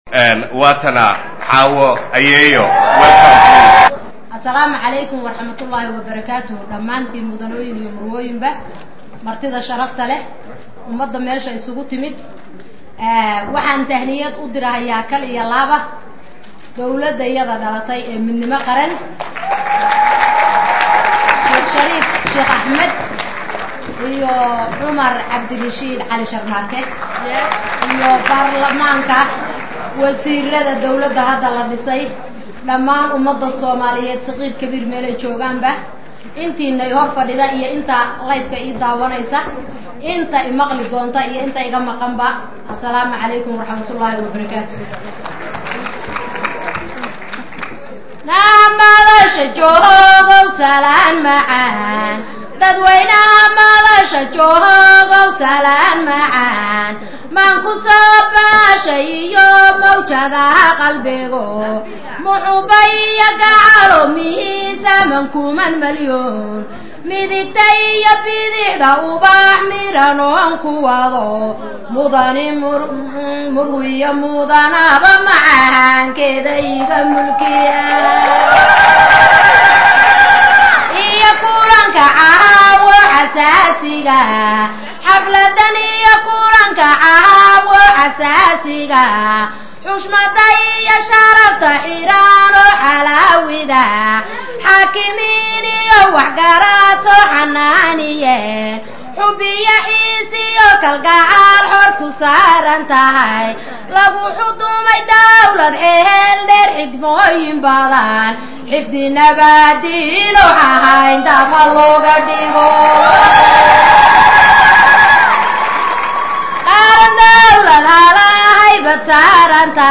Xafladd Dabaal deg iyo Damaashaad Wadatay lagu dhigay Convention Center, Minneapolis
Buraabur